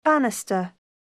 듣기반복듣기 [bǽnəstər]